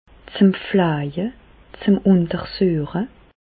Ville Prononciation 67 Reichshoffen